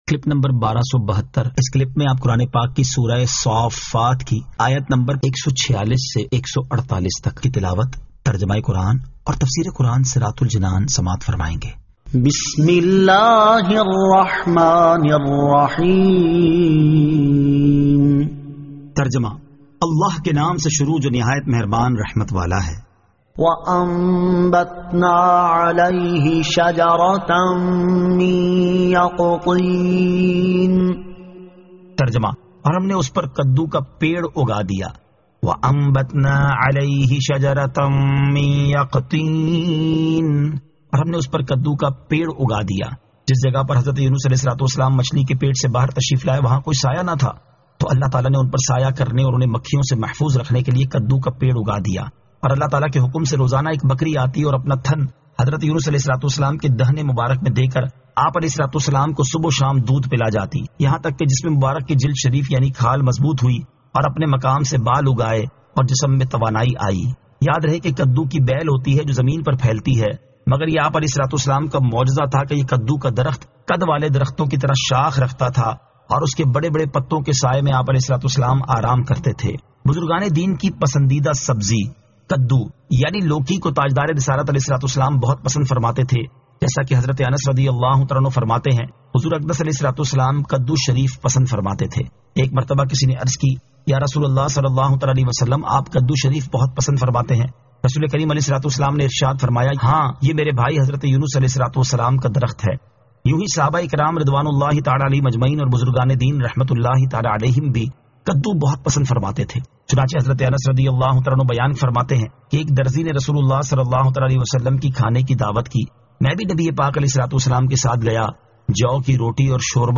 Surah As-Saaffat 146 To 148 Tilawat , Tarjama , Tafseer